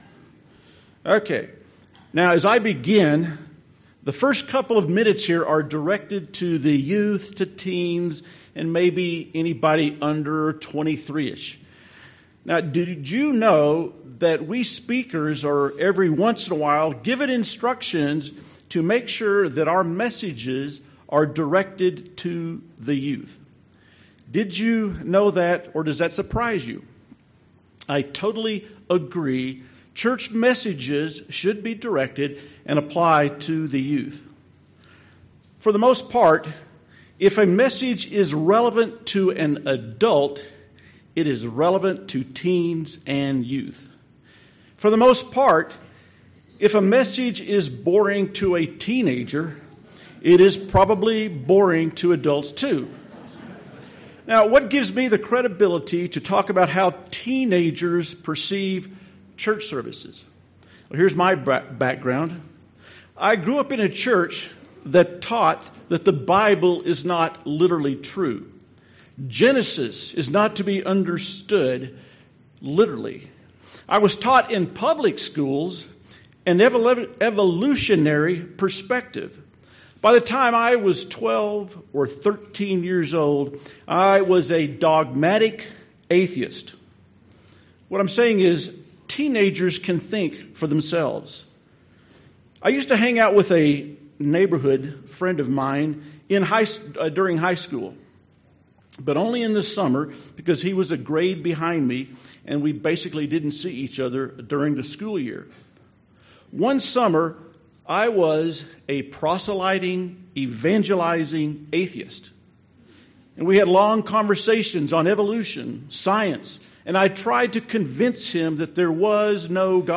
This sermon was given at the Steamboat Springs, Colorado 2019 Feast site.